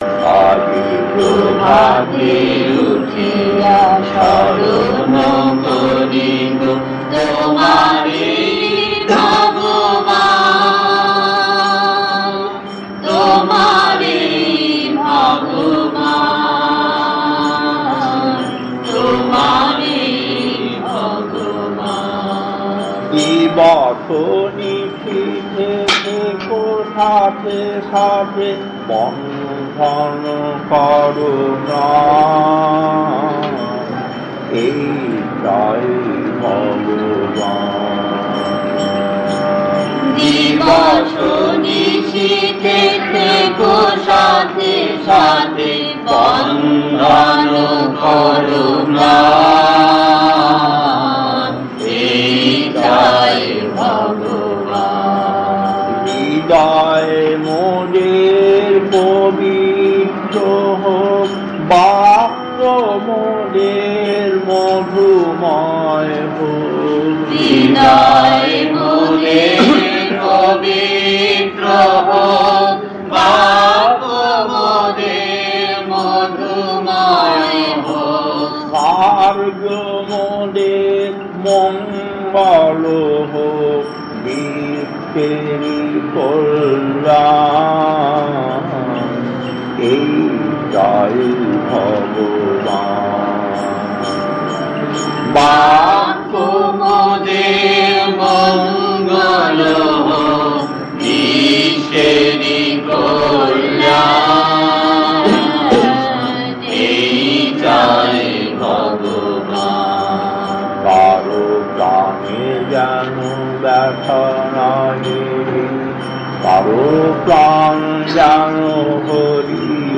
Kirtan A9-1 Matheran mid 80's 1.